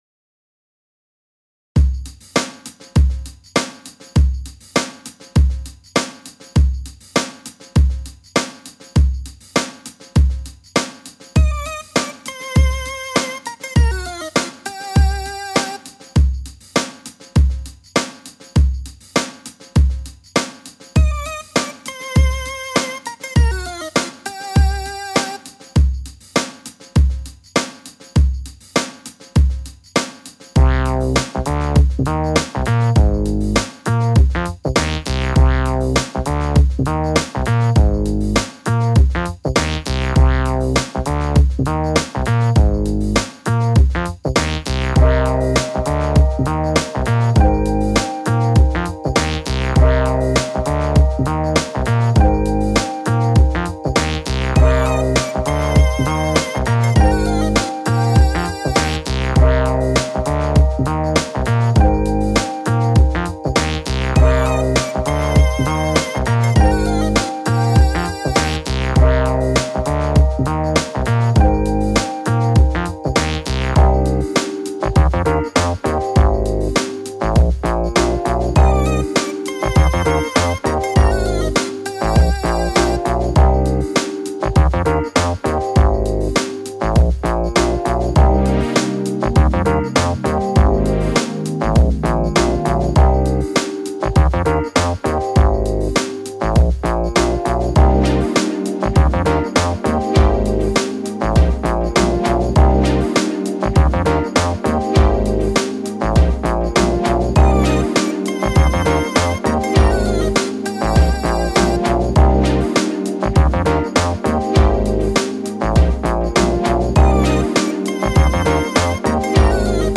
theme song
it's a bit 90ish